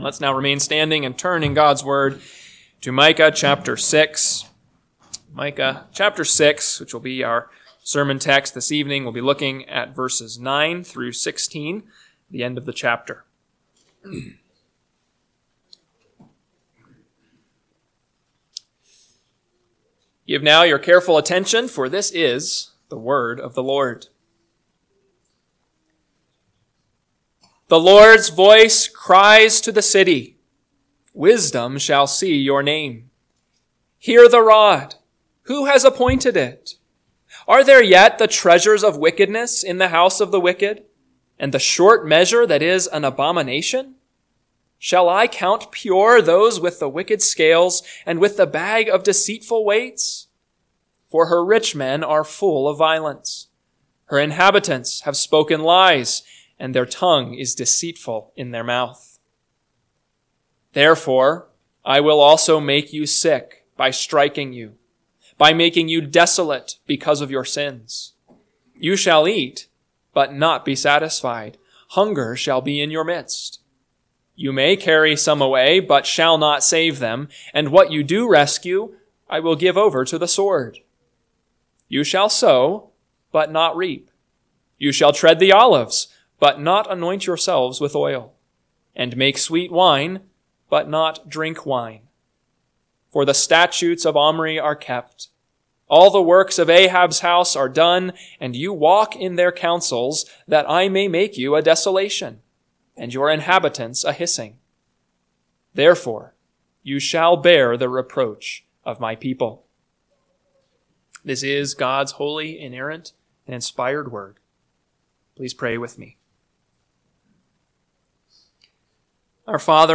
PM Sermon – 11/24/2024 – Micah 6:9-16 – Northwoods Sermons